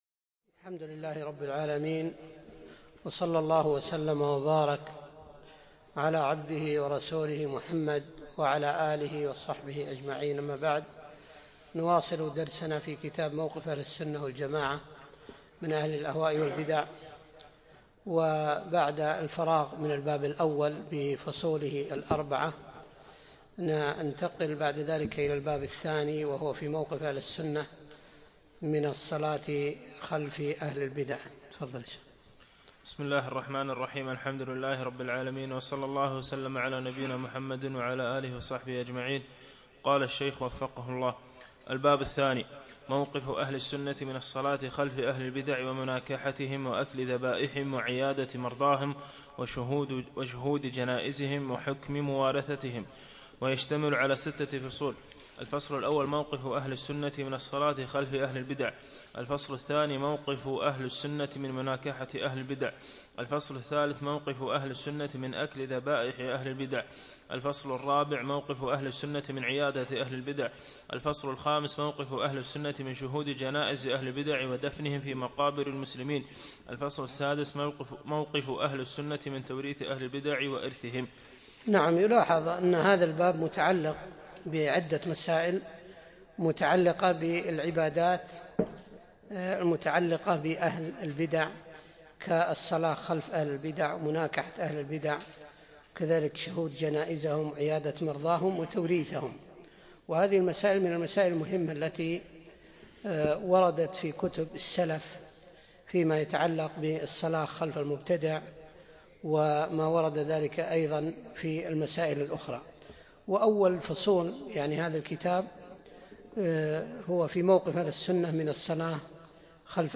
بعد المغرب يوم الثلاثاء 21 جمادى الأول 1437هـ الموافق 1 3 2016م في مسجد كليب مضحي العارضية